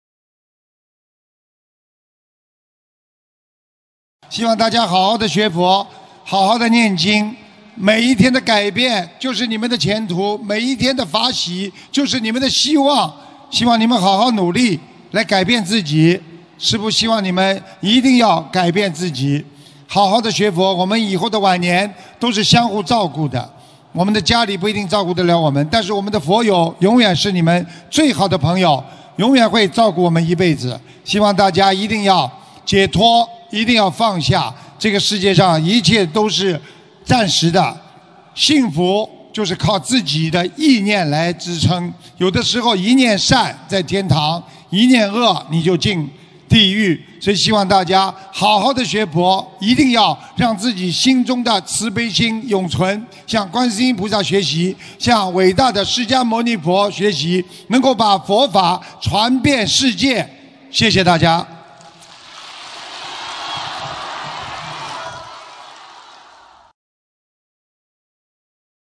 2019年9月8日法国巴黎法会结束语-经典开示节选